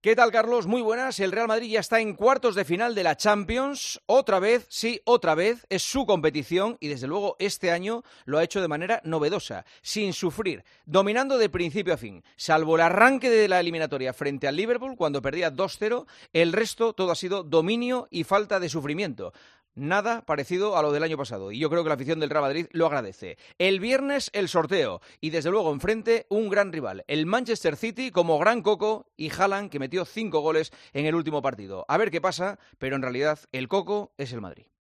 El presentador de 'El Partidazo de COPE' analiza la actualidad deportiva en 'Herrera en COPE'